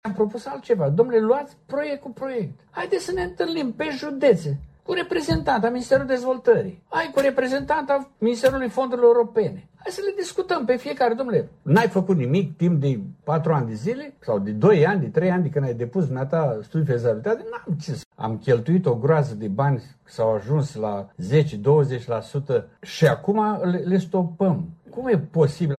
Primarul comunei Fălciu, Neculai Moraru, președintele Asociației Comunelor din România – filiala Vaslui, îi acuză pe cei din Guvern de lipsă de dialog.